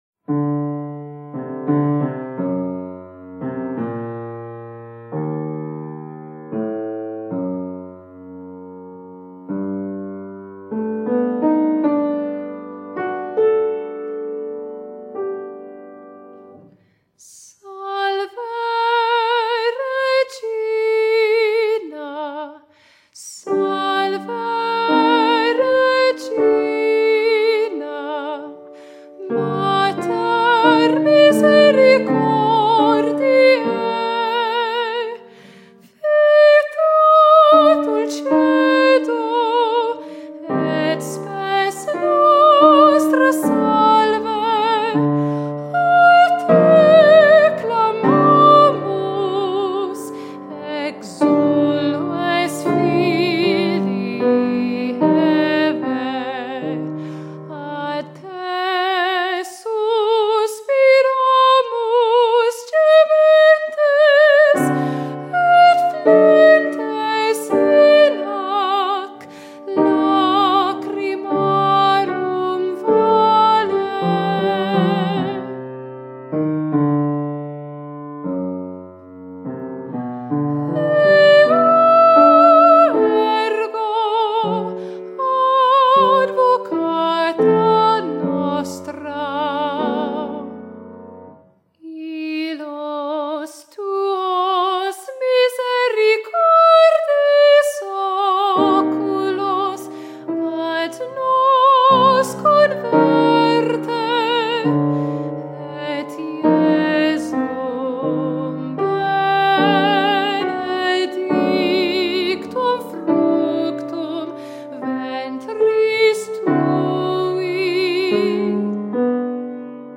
for soprano